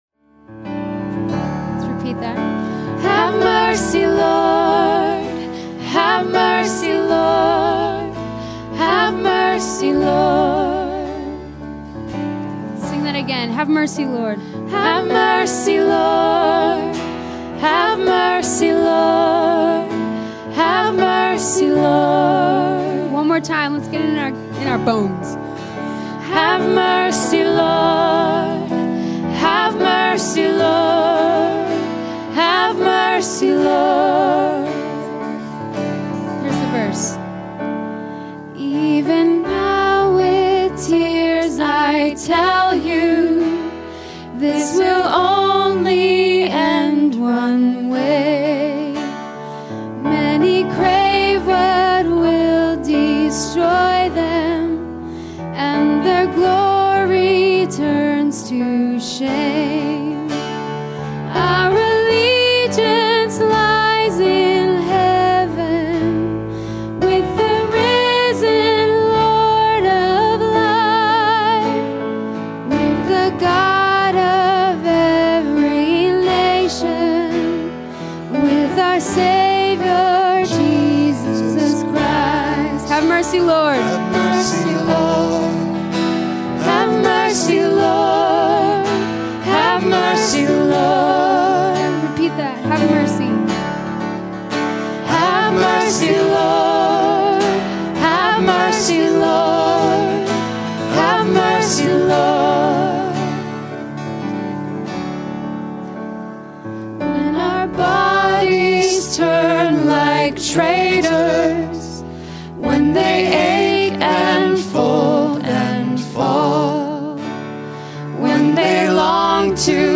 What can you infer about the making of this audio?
Presented at the Calvin Symposium on Worship 2011.